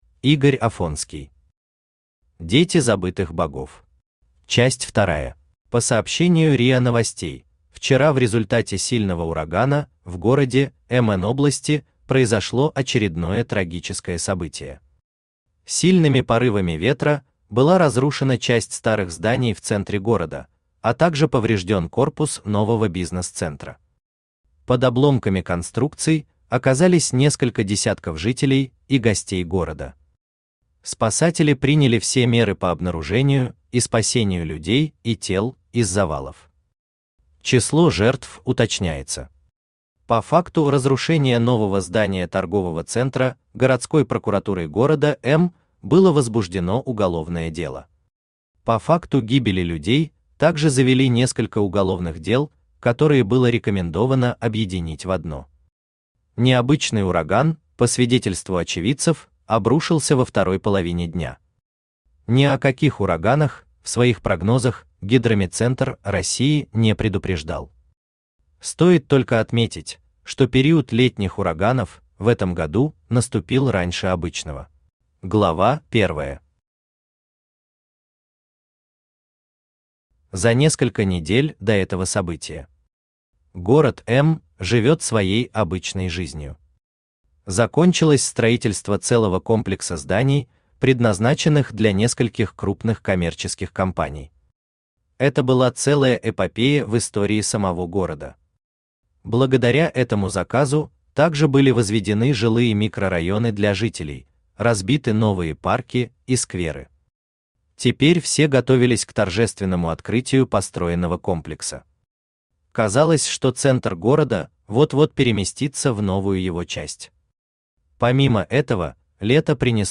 Аудиокнига Дети забытых богов. Часть вторая | Библиотека аудиокниг
Читает аудиокнигу Авточтец ЛитРес.